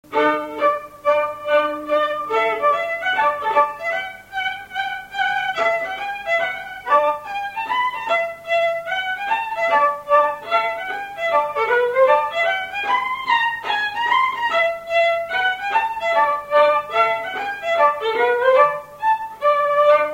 Scottish anglaise
Saint-Hilaire-de-Chaléons
Résumé instrumental
Usage d'après l'informateur gestuel : danse